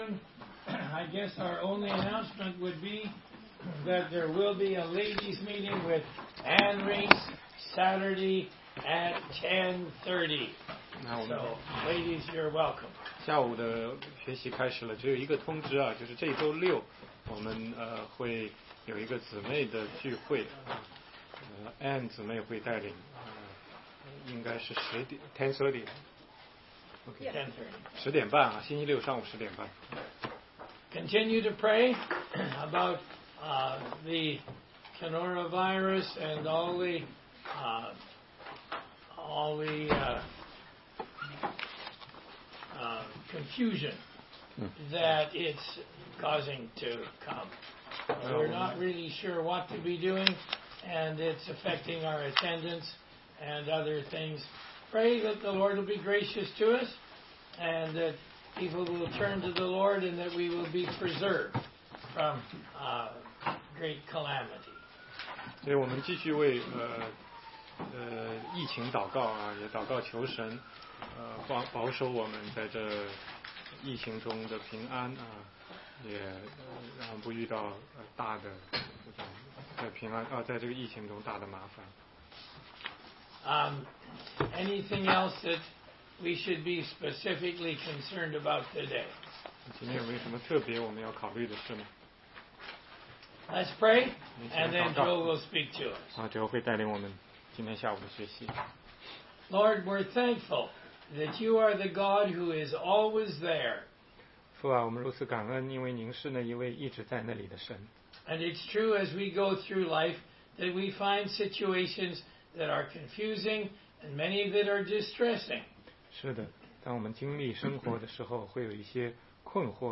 16街讲道录音 - 人生至要问题的答案系列之九：尼哥底母,你必须重生！